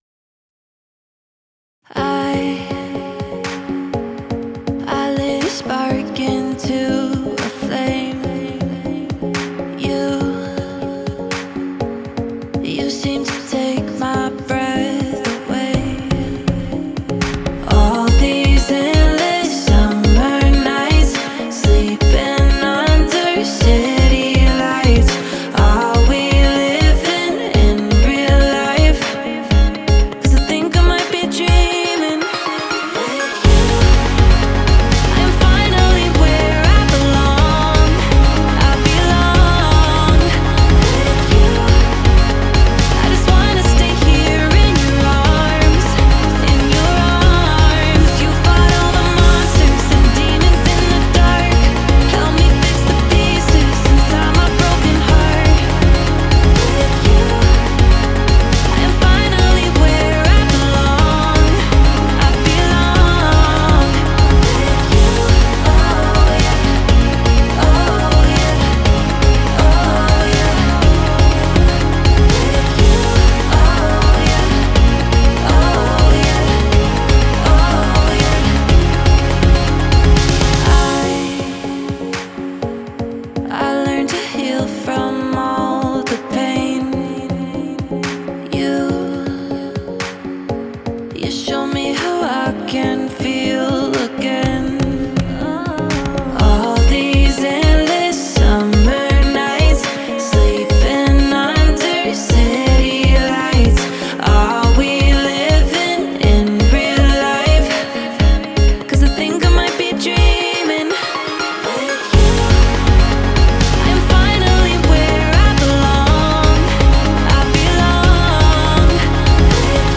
I Belong - Please critique - Pop Song (Critique 2nd mix version) - updated
This is the most number of tracks I did (40 tracks) Please let me know.